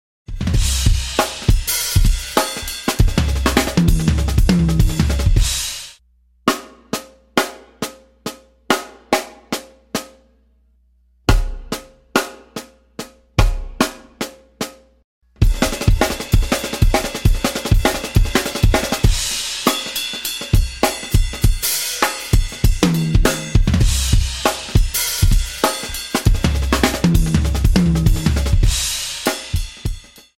🥁 This 9-note sticking pattern — R l R l l R L r l — with kicks on the 1st and 6th notes creates a smooth, rolling groove once you get up to tempo. I’ve been practicing this one in triplets to lock in that flowing, rolling feel.